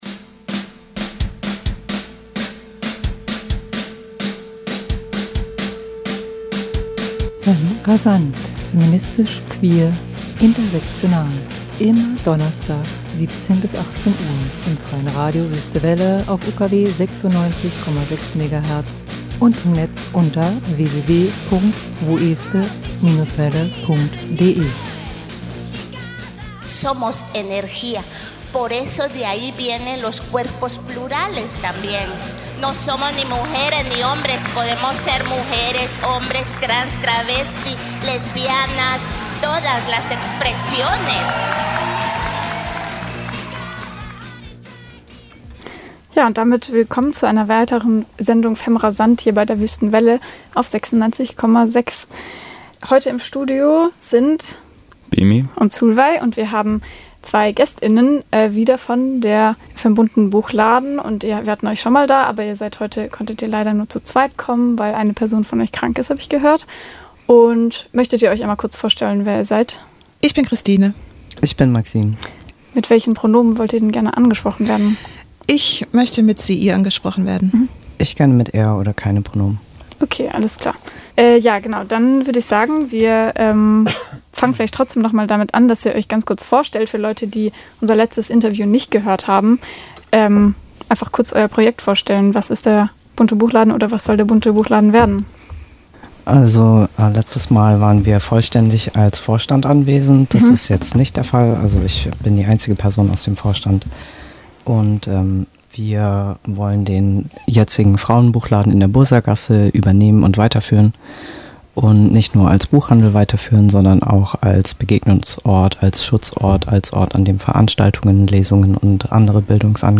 Im Interview ging es um den Bunten Buchladen, welcher den aktuellen Frauenbuchladen 2026 weiterführen und neu denken soll.